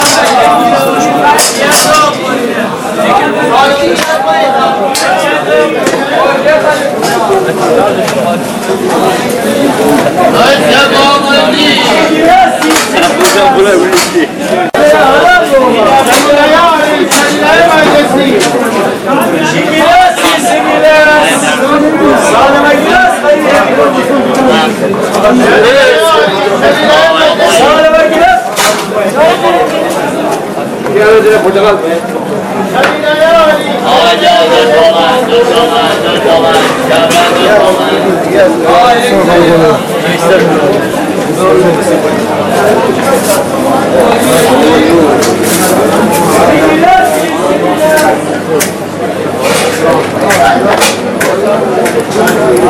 [صدای بازار خرید و فروش محلی]
صدای بازار محلی